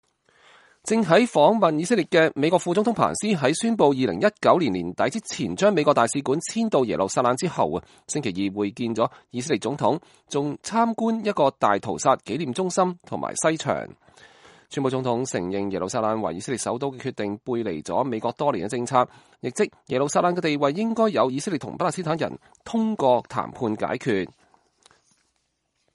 彭斯副總統2018年1月22號在以色列議會講話